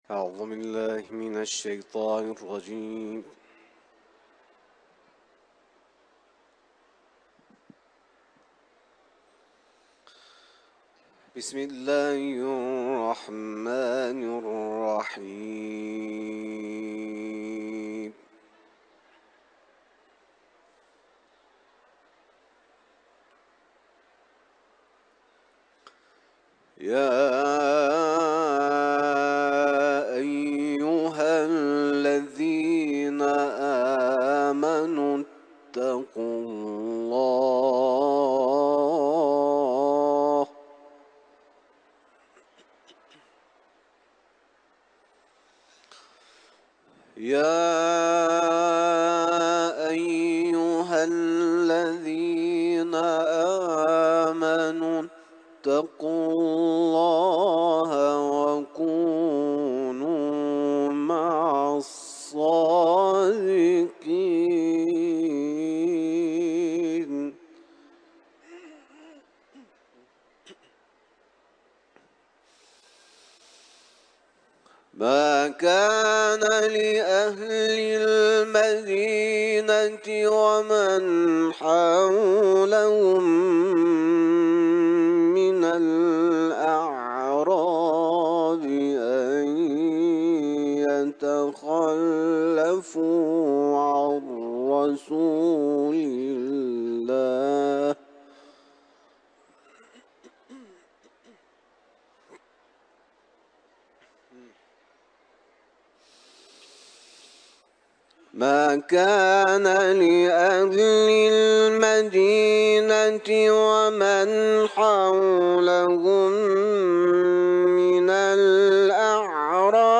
سوره توبه ، تلاوت قرآن